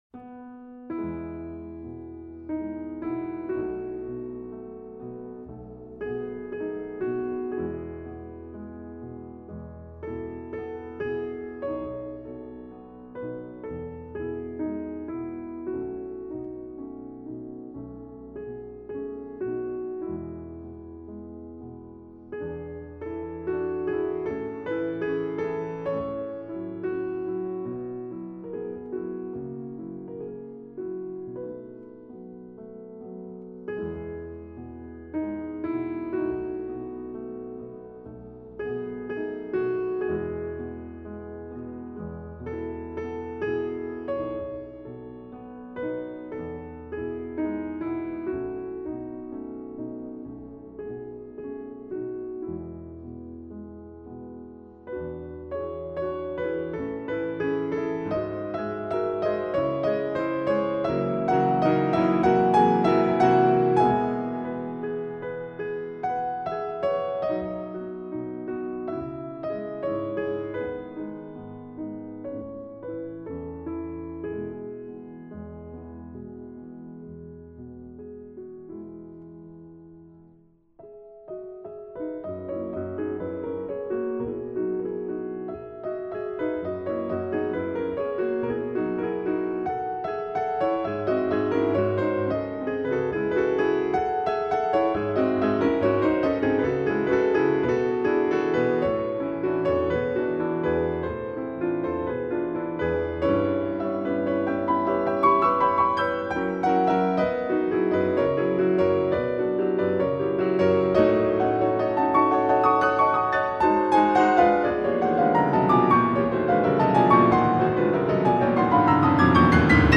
巴赫、莫扎特、贝多芬、舒伯特、肖邦、舒曼、李斯特等作曲家著名钢琴小品。